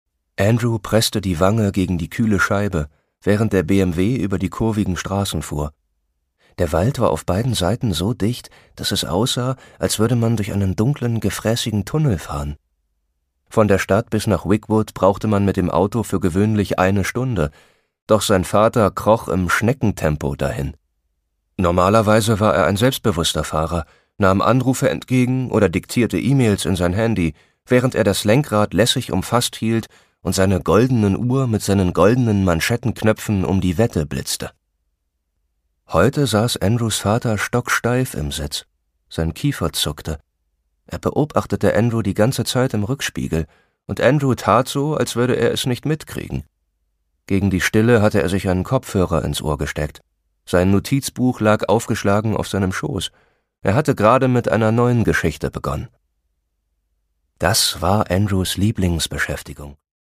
Produkttyp: Hörbuch-Download
erzählt melancholisch verträumt und düster fesselnd das romantische Schauermärchen. Dabei lotet er die psychologischen Tiefen gekonnt und einfühlsam aus.